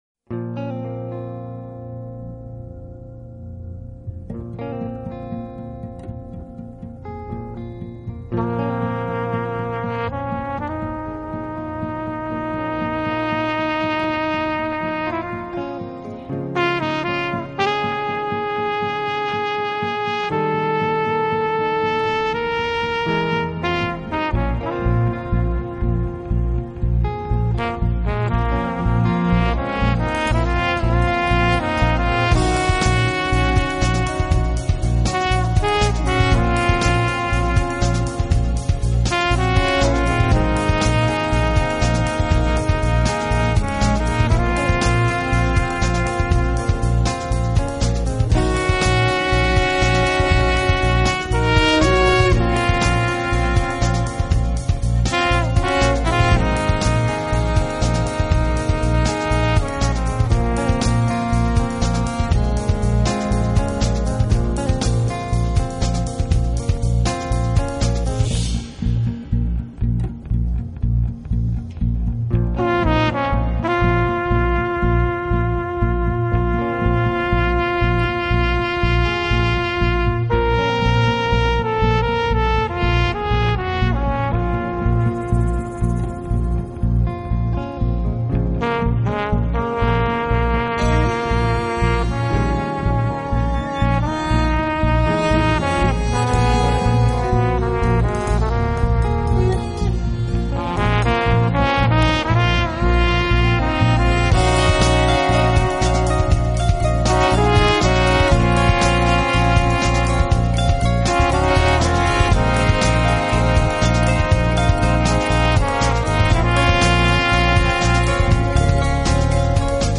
Genre: Smooth jazz
Vibraphone
laid-back music